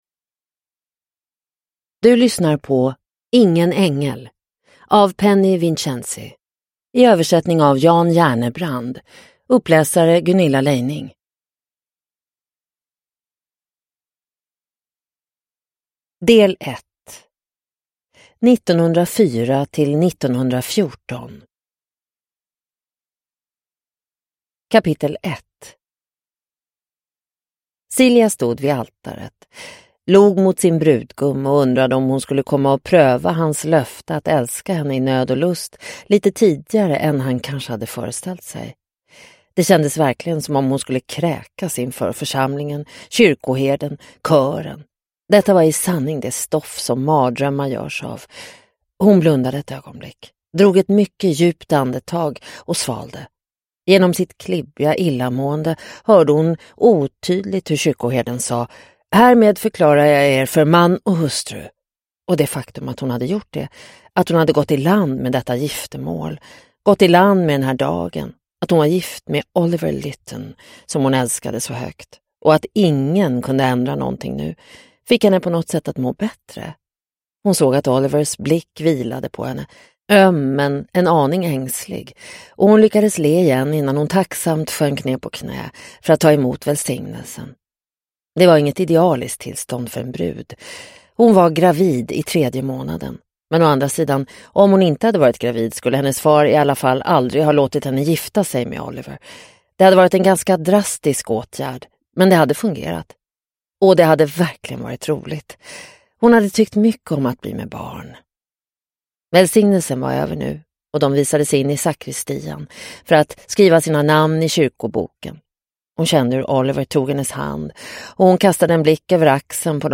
Ingen ängel – Ljudbok – Laddas ner